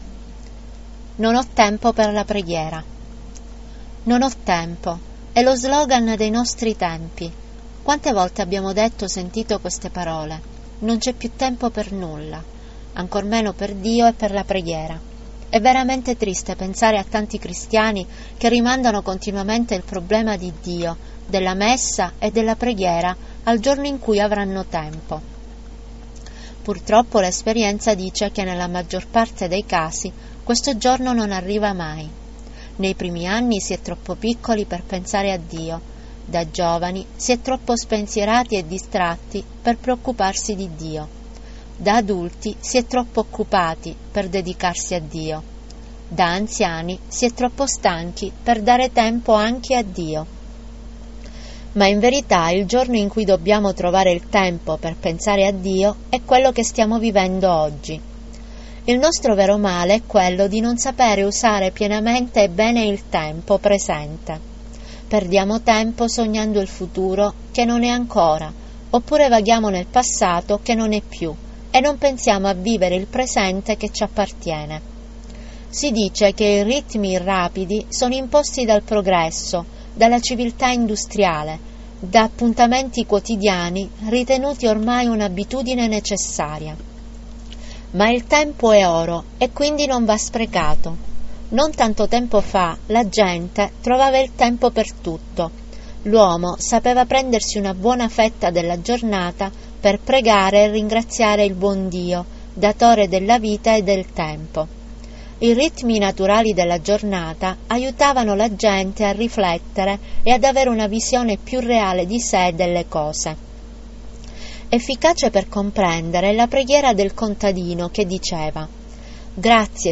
Ottava puntata dell'audio guida sulla preghiera.